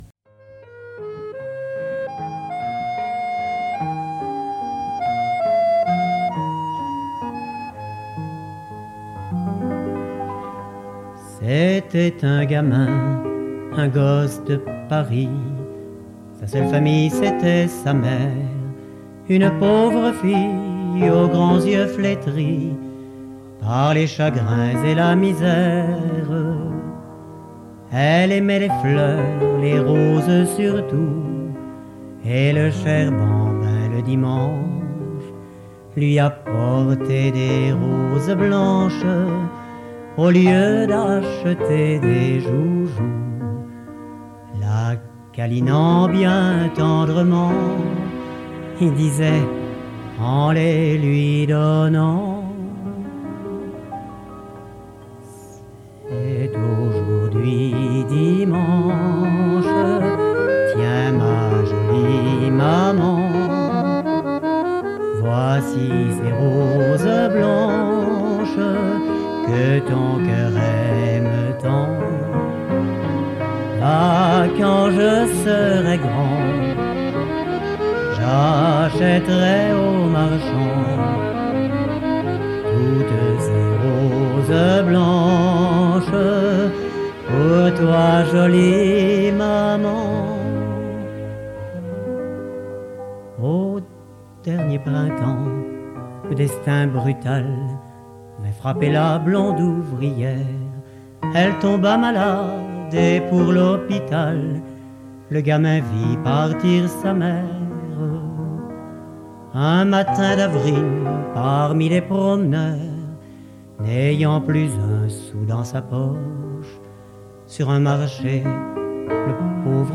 Enregistr�e en public en 1985